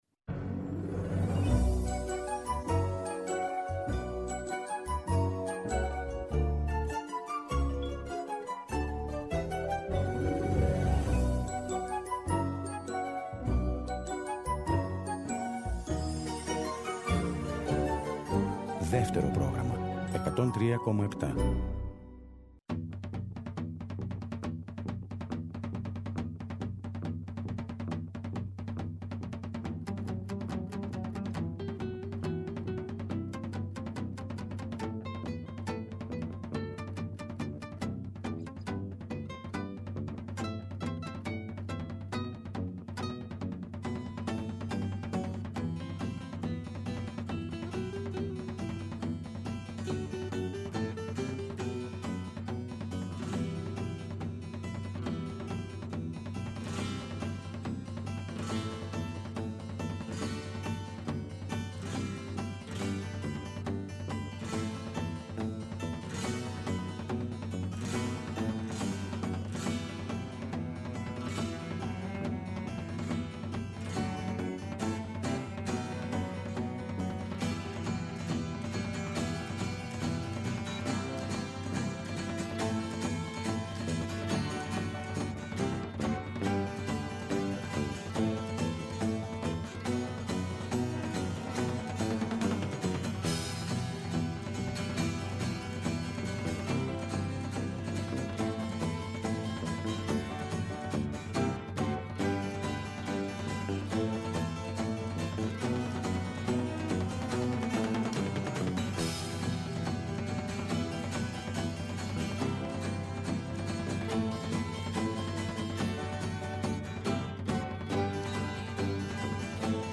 Στο δεύτερο μέρος, δημοφιλείς τραγουδιστές από τα περισσότερα είδη του ελληνικού τραγουδιού, καταξιωμένοι ήδη αλλά και νεότεροι, ερμήνευσαν τραγούδια από το πρόσφατο ρεπερτόριό τους, αλλά και τραγούδια που, με στιχουργικές αλλαγές, έμμεσα ή άμεσα, συνδέονταν με την επταετία του δικτατορικού καθεστώτος, με την πρόσφατη εισβολή στην Κύπρο, αλλά και με το κλίμα των πρώτων ημερών της Μεταπολίτευσης.